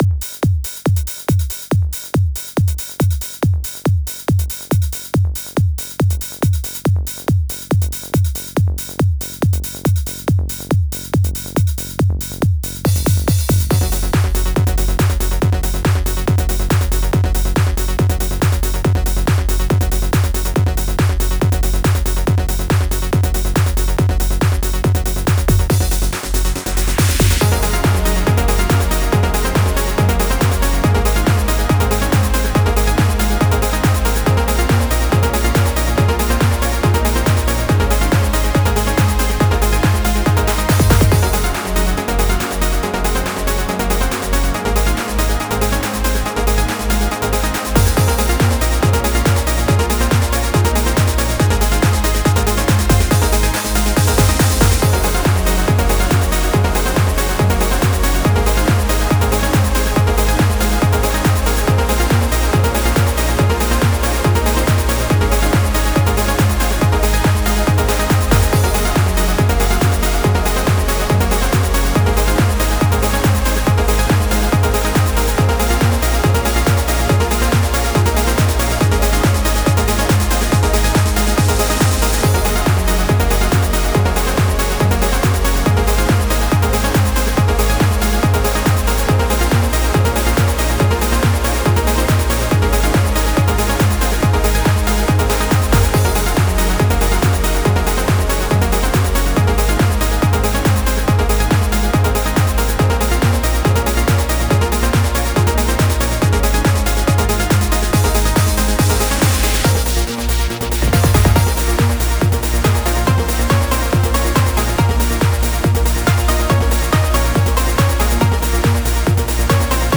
Trance
Style: Trance
rather fast production